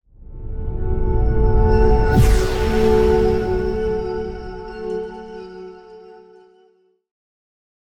moonbeam-outro-v1-004.ogg